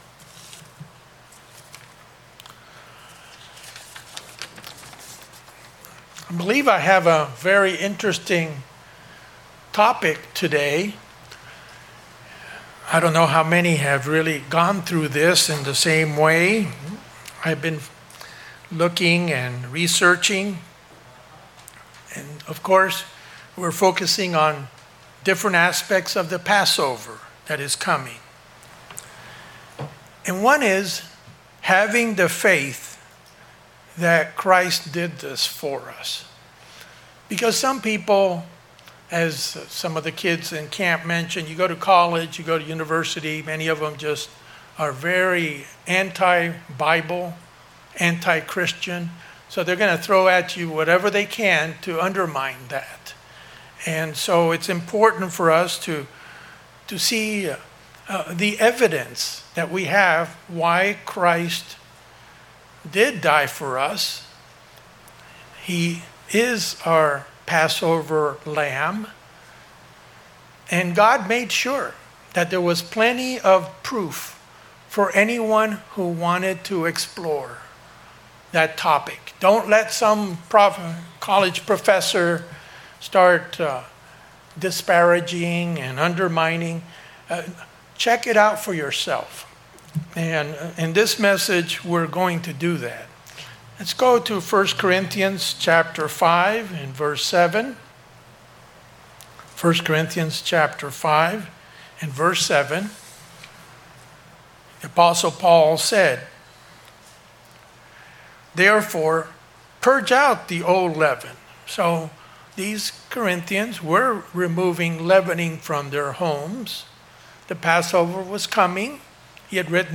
In this message, we'll look at Old Testament prophecies concerning Christ. What are the mathmatical probabilities of prophecy being fulfilled? Which OT Book has the most prophecies?